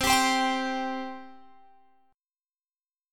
C5 chord {8 x 5 5 8 8} chord